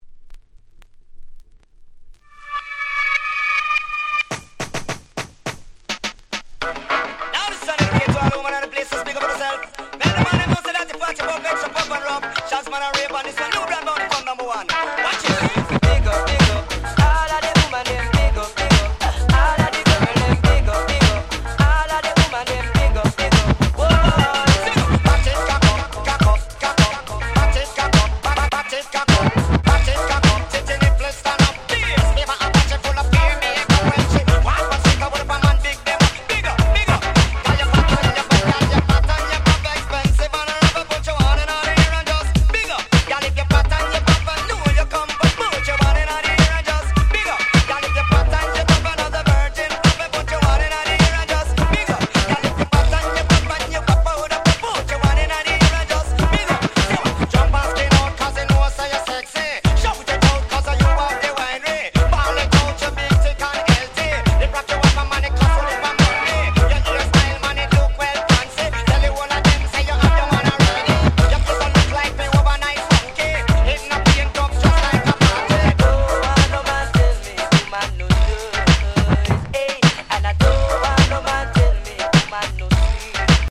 Nice Mash Up / Remix !!